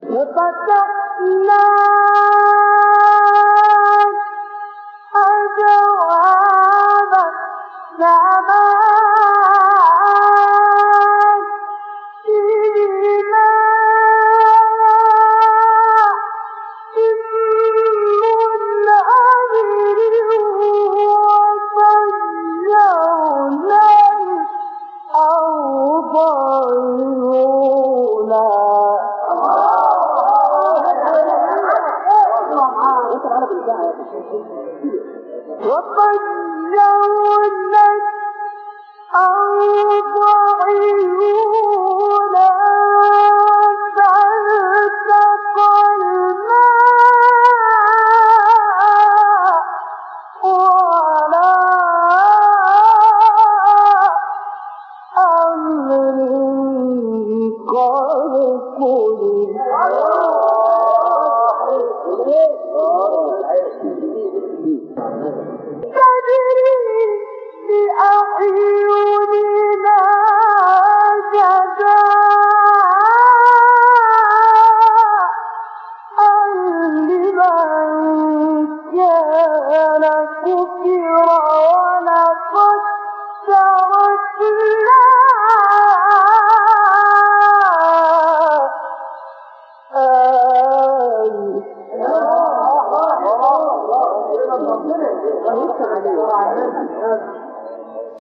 مقام الحجاز